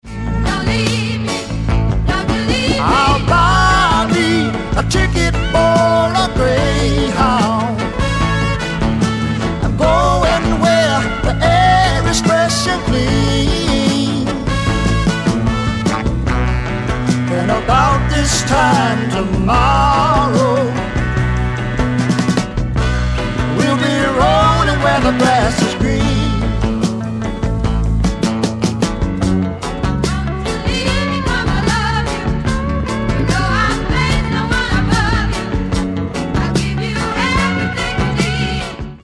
ソリッドなリズム・セクションとリズミカルなホーンをフィーチャーしたスワンプ・ロック傑作
ブルー・アイド・ソウル感溢れるふたりのヴォーカルのコンビネーションも最高の一枚！